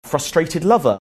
So sweet LOVER means ‘lover who is sweet’.
frustrated LOVER (lover who is frustrated)